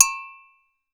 AFRCN AGOGOS